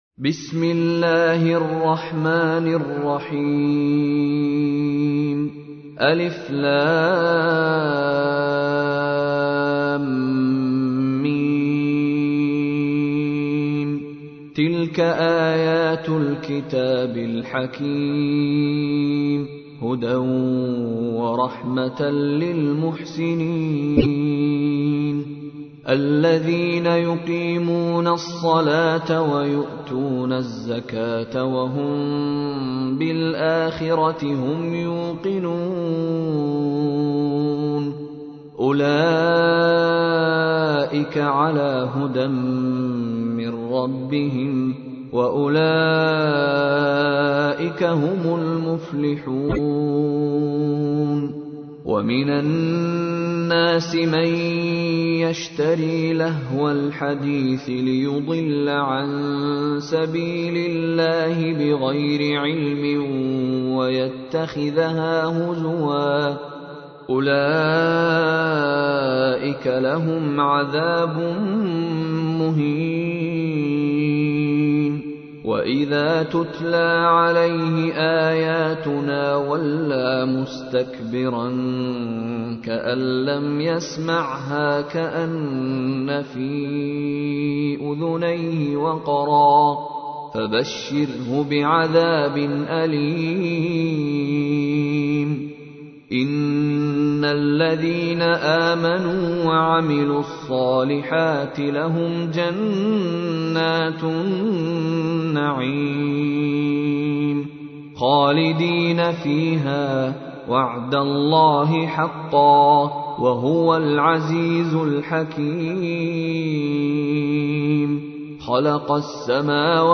تحميل : 31. سورة لقمان / القارئ مشاري راشد العفاسي / القرآن الكريم / موقع يا حسين